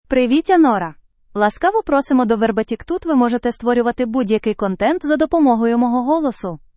Nora — Female Ukrainian AI voice
Nora is a female AI voice for Ukrainian (Ukraine).
Voice sample
Listen to Nora's female Ukrainian voice.
Nora delivers clear pronunciation with authentic Ukraine Ukrainian intonation, making your content sound professionally produced.